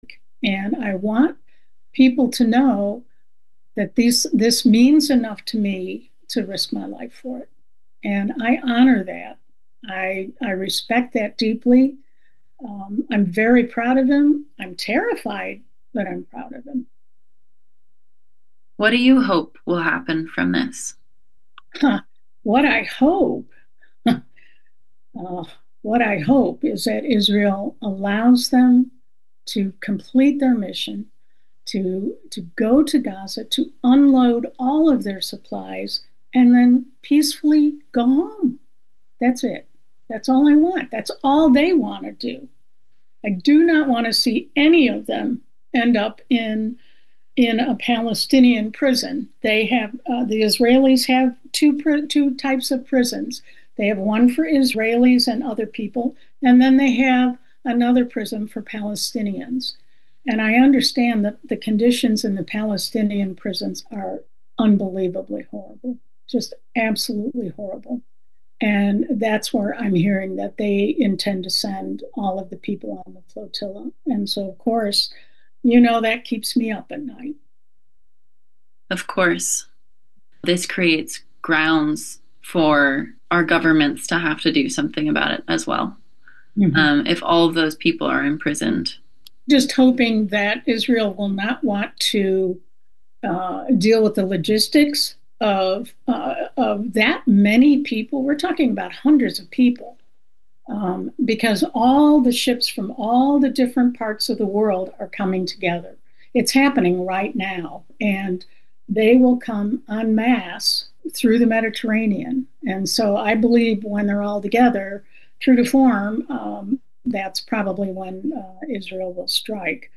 Labor Radio